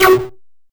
LaserWeapon.wav